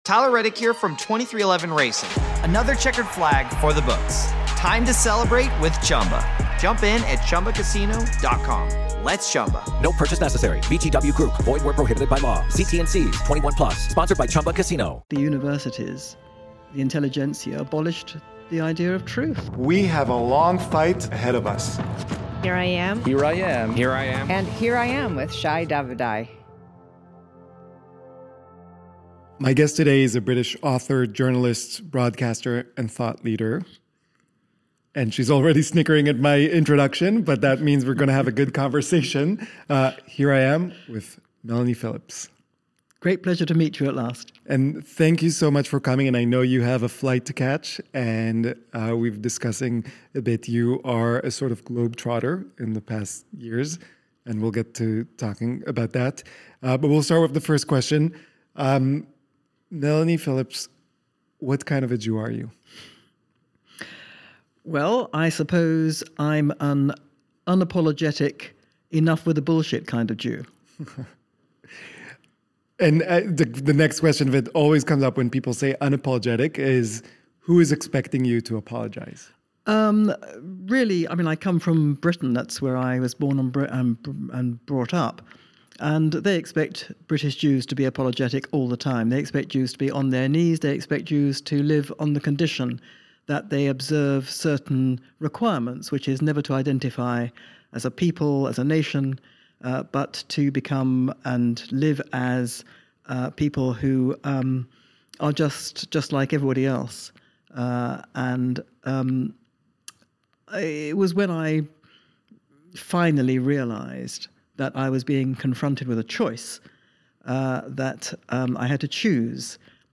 The episode concludes with a lightning round, offering personal insights into Melanie’s life, beliefs, and hopes for the future. Guest: Melanie Phillips Consider DONATING to help us continue and expand our media efforts.